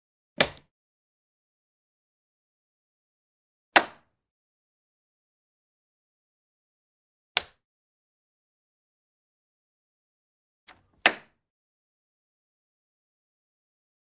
Plastic Vinyl Sliding Window Lock Sound
household